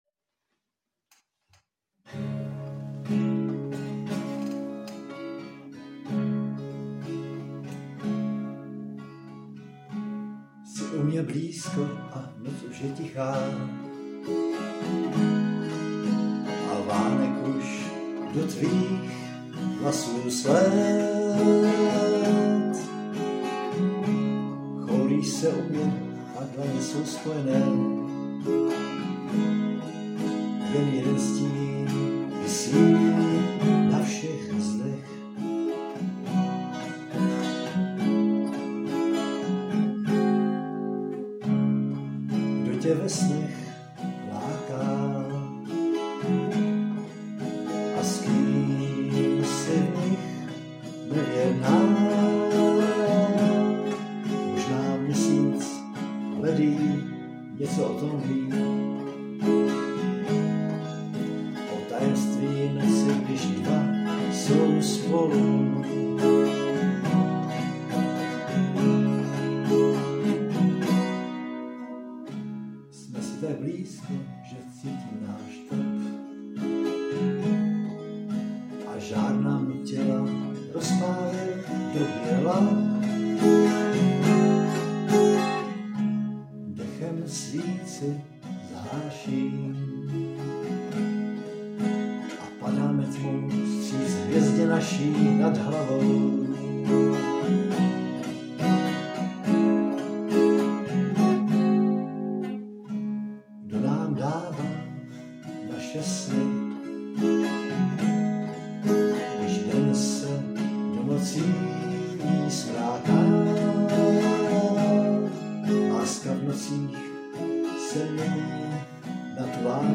Krása. I ta kytara s Tvým podáním. Super.